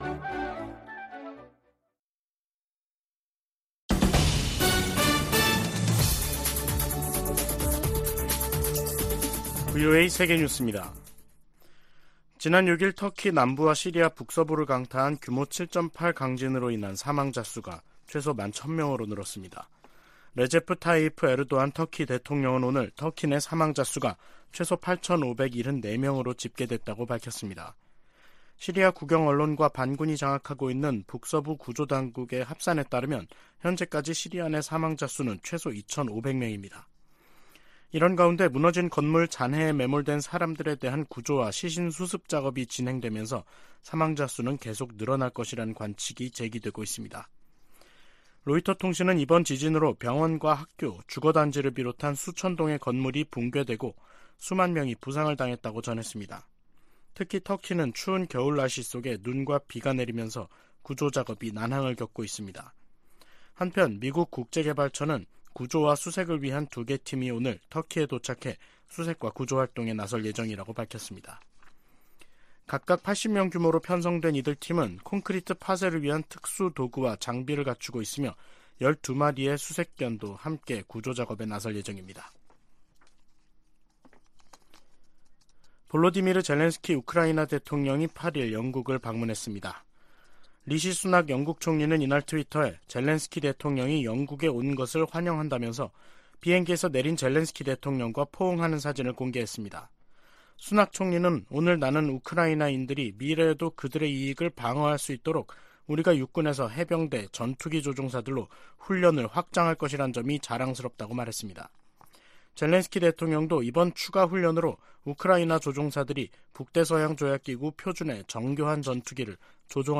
VOA 한국어 간판 뉴스 프로그램 '뉴스 투데이', 2023년 2월 8일 3부 방송입니다. 조 바이든 미국 대통령은 2일 국정연설을 통해 중국이 미국의 주권을 위협한다면 ‘우리는 나라를 보호하기 위해 행동할 것’이라고 말했습니다. 유엔이 국제적 긴장을 고조시키는 북한의 핵 개발과 미사일 발사에 우려한다는 입장을 거듭 확인했습니다.